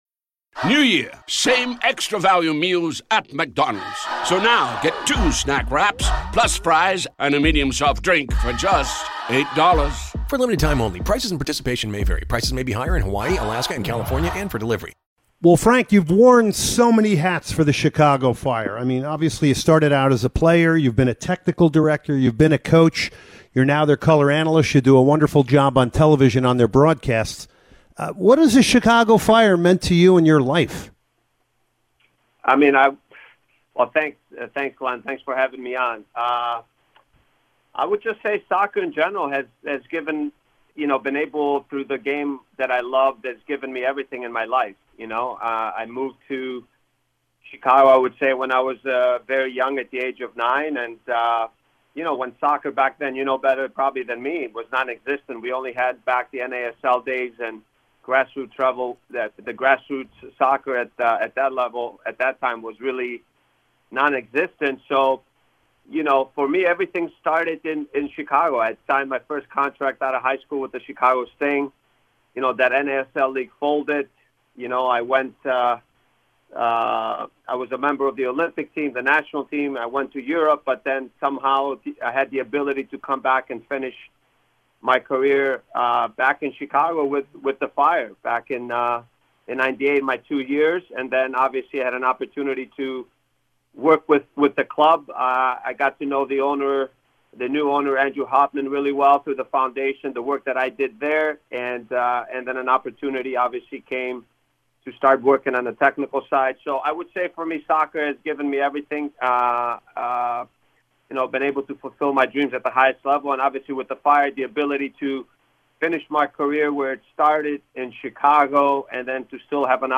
Soccer Matters Extra Time Interview with Frank Klopas